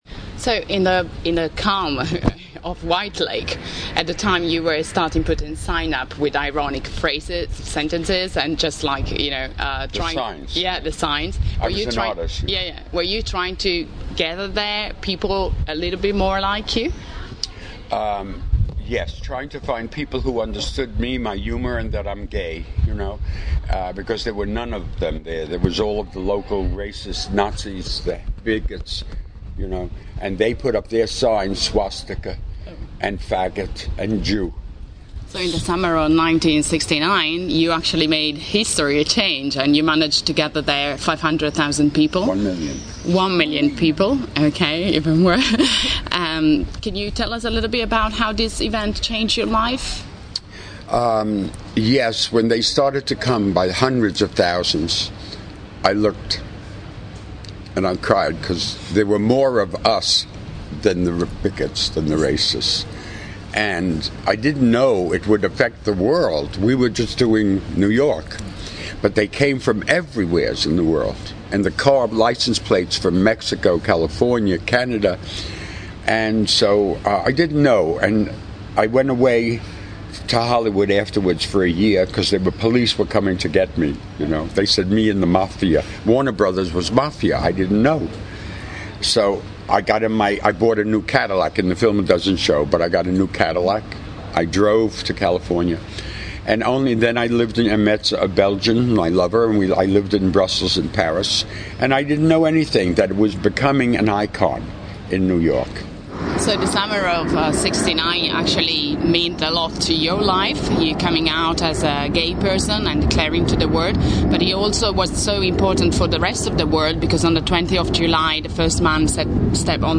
Tre giorni di pace, amore e musica al Biografilm Festival